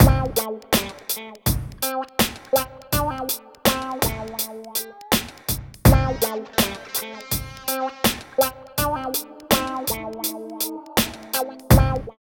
134 LOOP  -L.wav